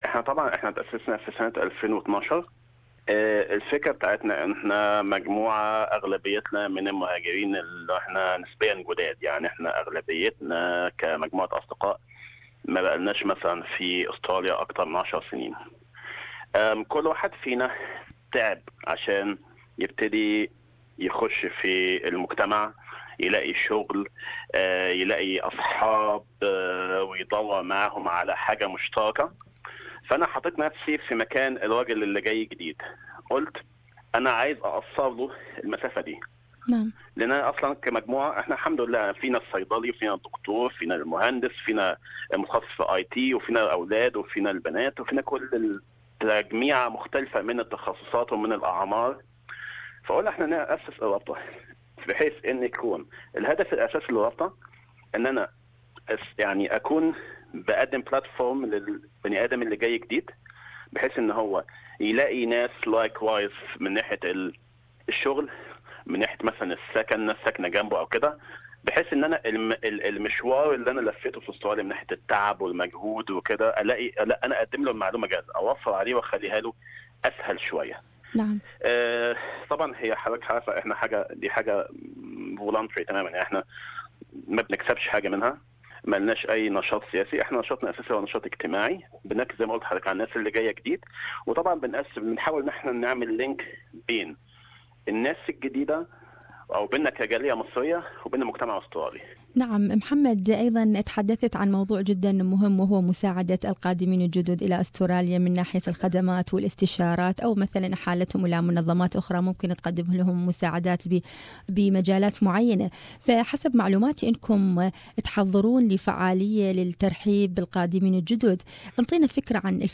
SBS Arabic